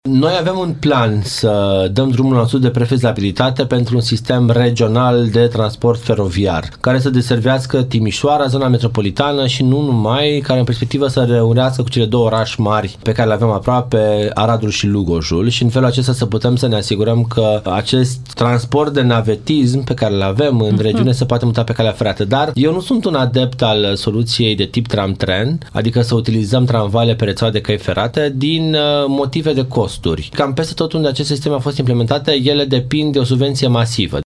Viceprimarul Ruben Lațcău spune că acest tip de transport ar putea deservi călătorii care fac naveta la Timișoara.